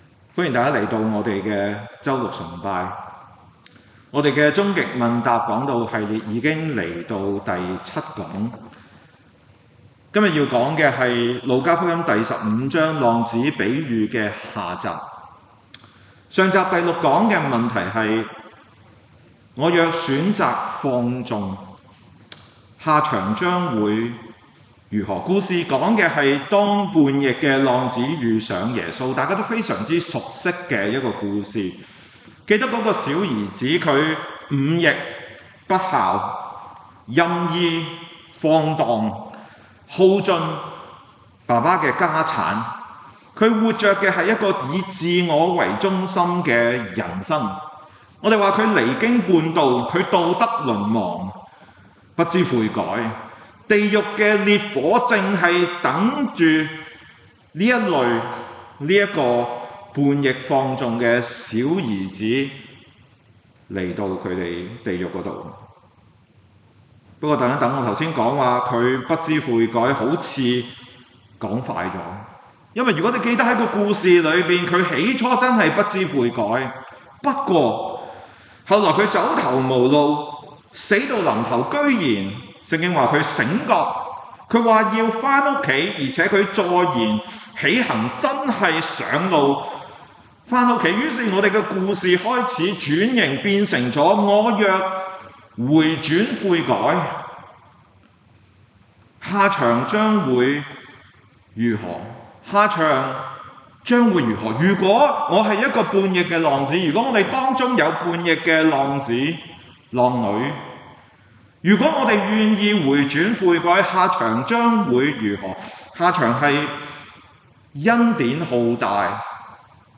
場所：中華基督教會公理堂週六崇拜